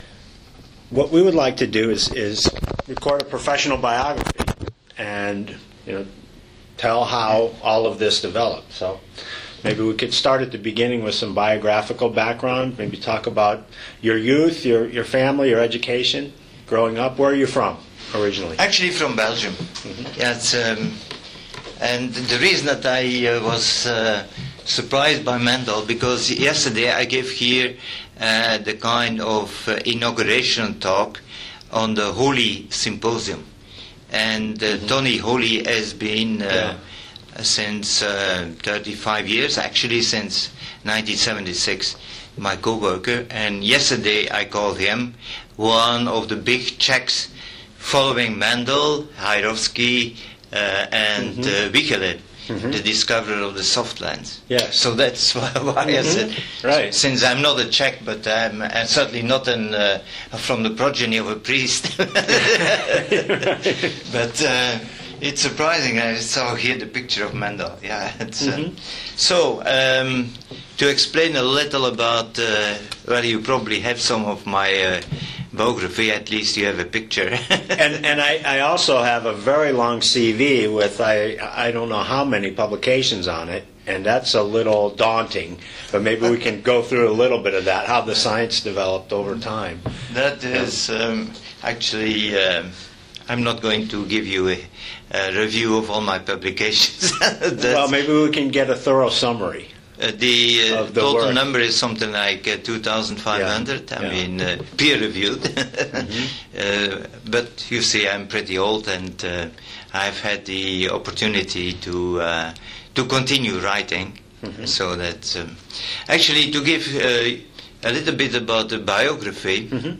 Research interview with Erik De Clercq
Oral histories